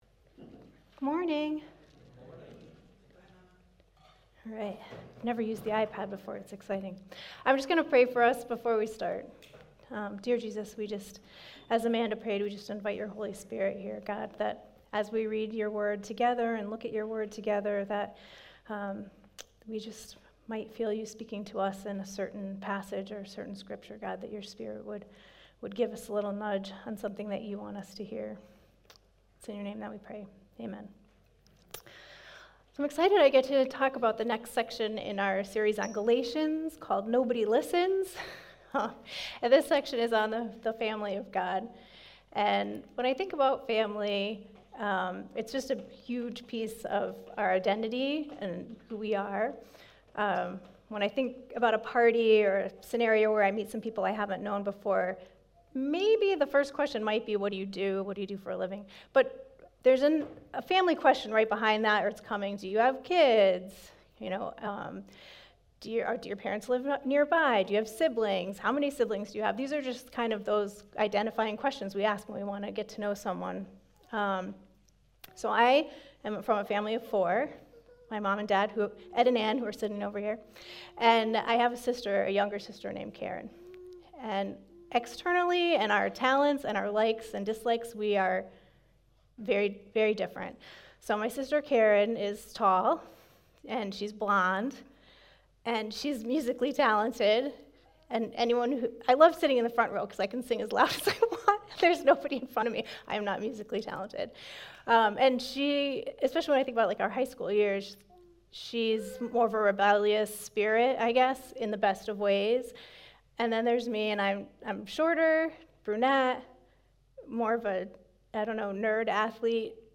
Listen in as we continue our series on Galatians! Timestamps: Welcome & Worship: 4:14 The Lord’s Prayer (Multi-language): 10:39 Worship: 16:55 Sermon: 28:03 Worship: 55:11 Offering and Announcements: 1:00:58 Song List: God So Loved Holy Spirit One Thing Remains Who You Say I Am